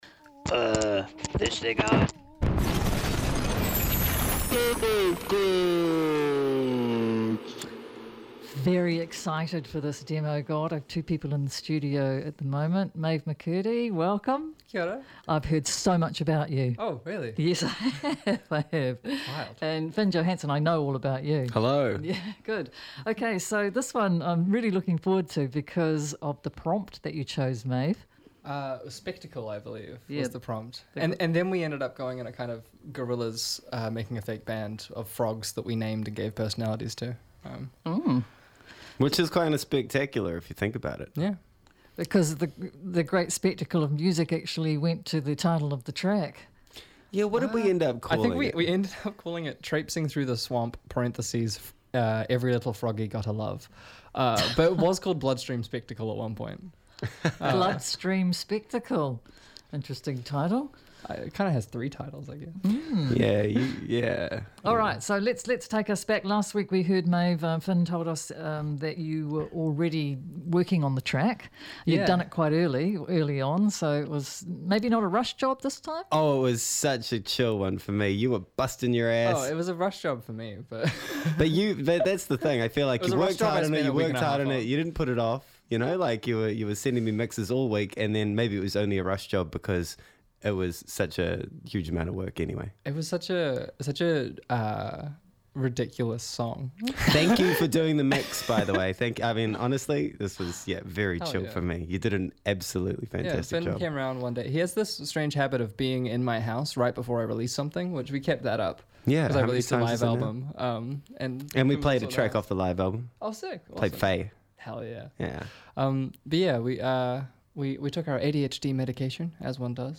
for an entertaining chat and the unveiling of the song that takes you to places you didn’t even know you wanted to go.